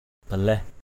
balaih.mp3